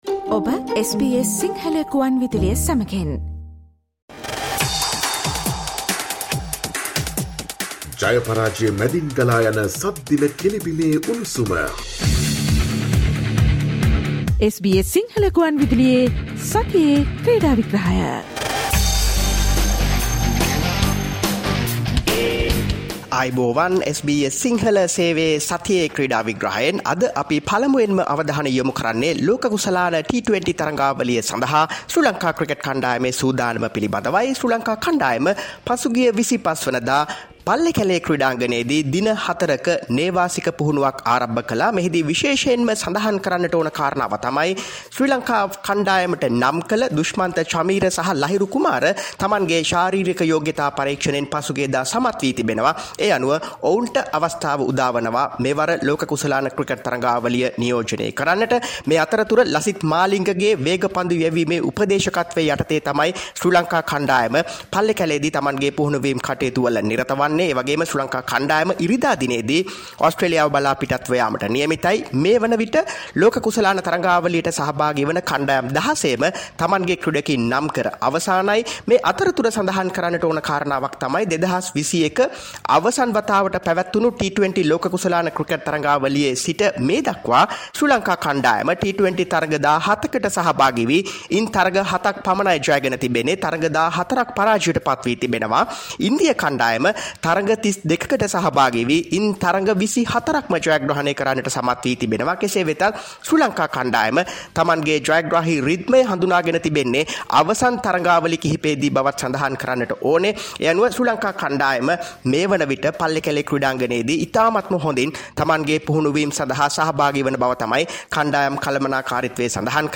Listen to the SBS Sinhala Radio weekly sports highlights every Friday from 11 am onwards